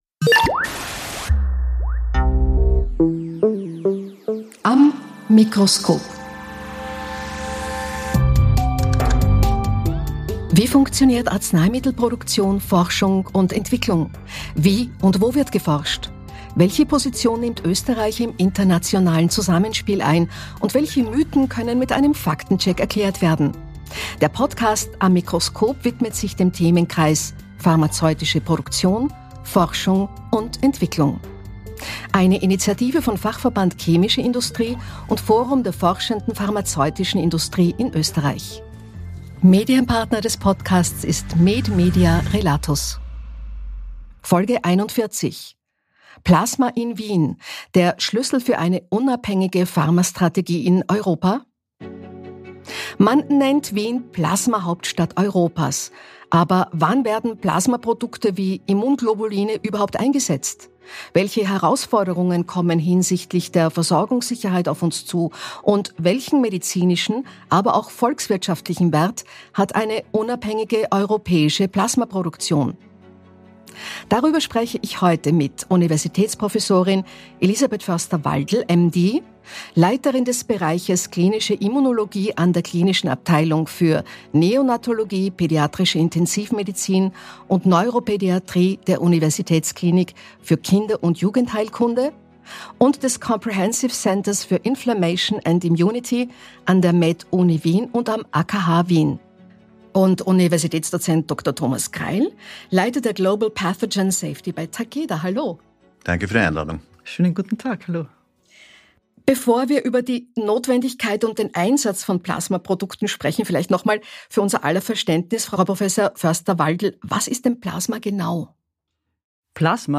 Folge von „Am Mikro|skop“ mit Moderatorin Martina Rupp.